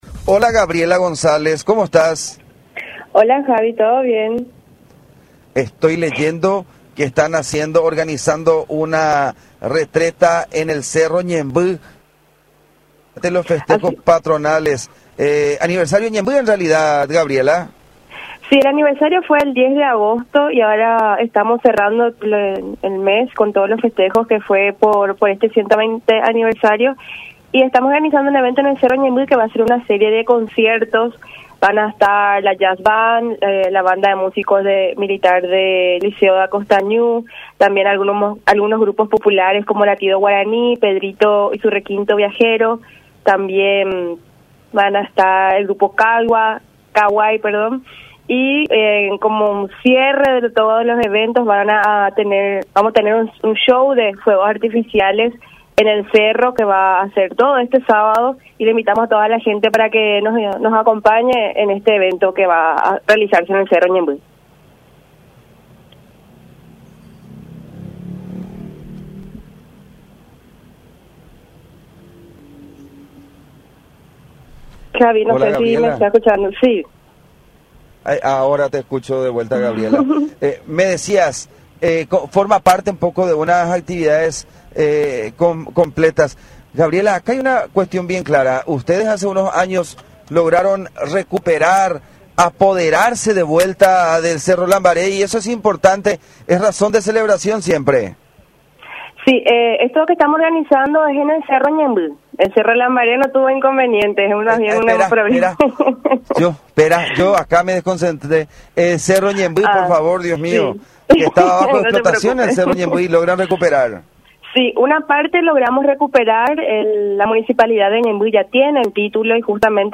en comunicación con La Unión.